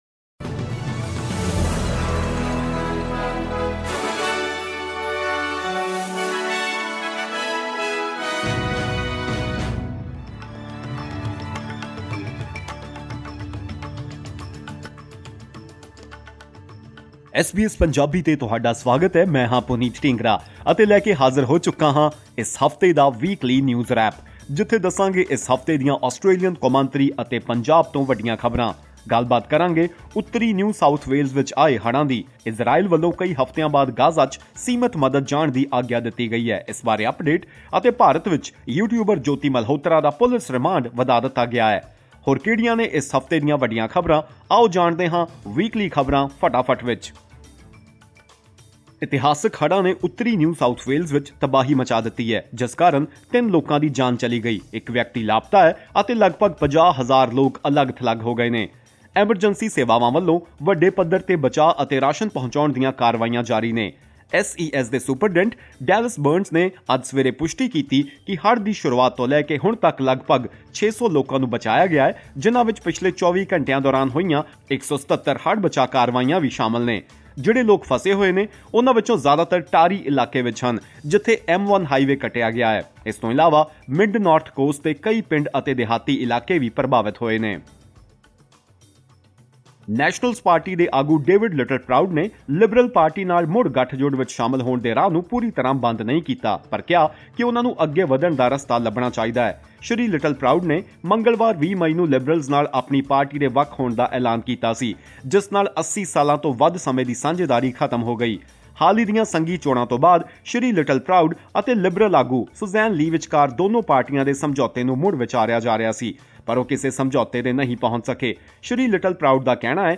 Find out in this weekly news roundup.